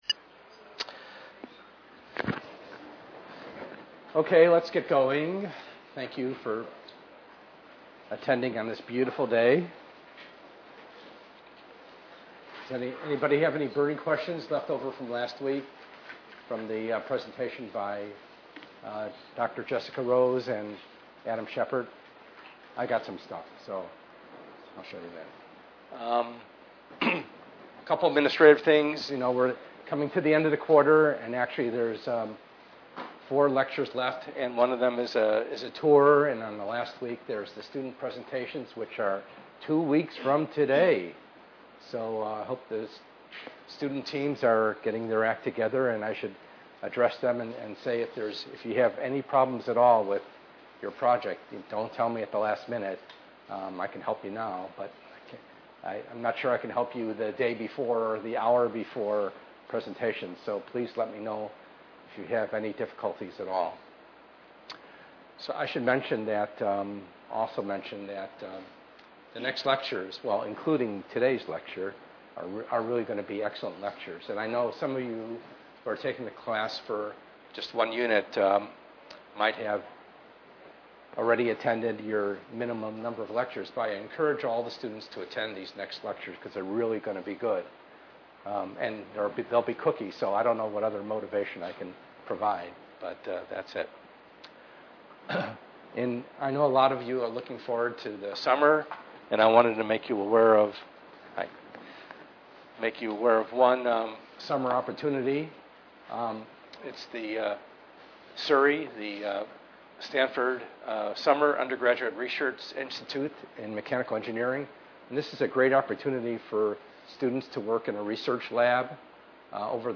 ENGR110/210: Perspectives in Assistive Technology - Lecture 08a